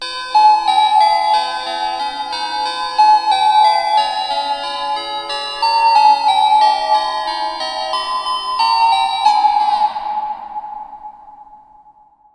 Звук коллапса подарка Санты